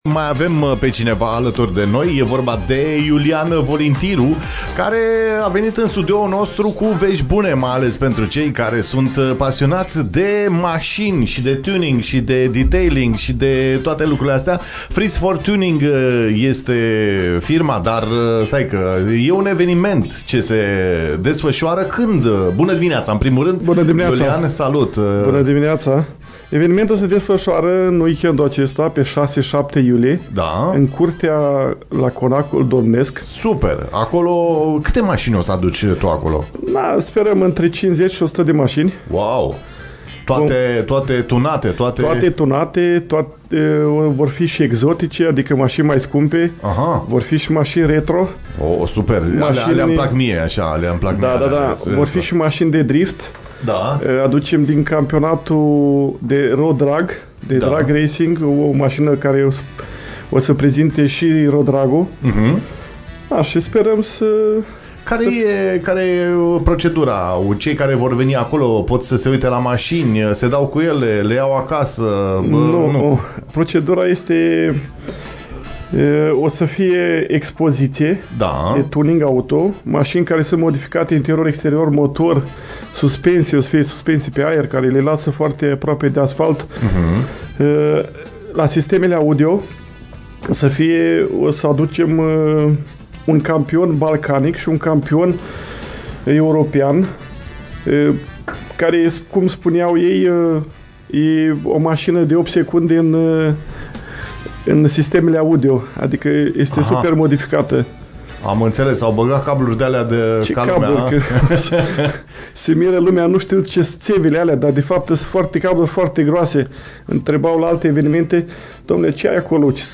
invitat la DIS DE DIMINEAȚĂ